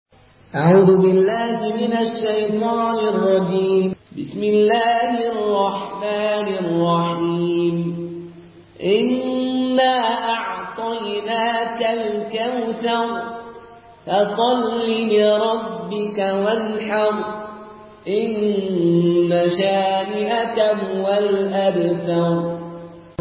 Murattal Qaloon An Nafi